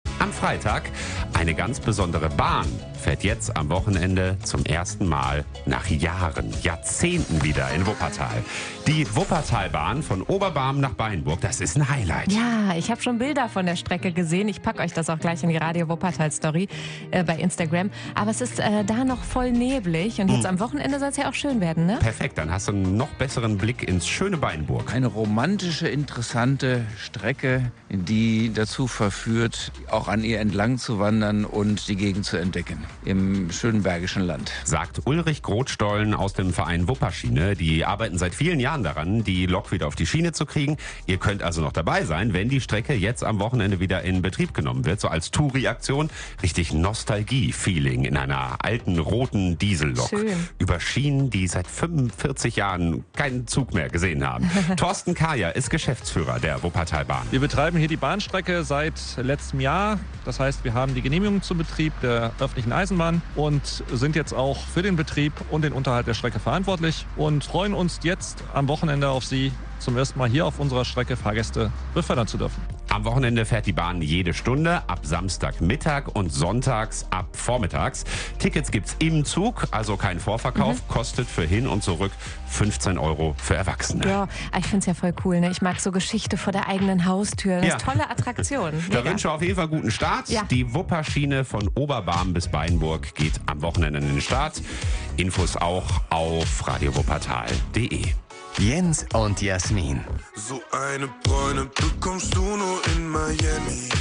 Interview mit der Wupperschiene, Teil 1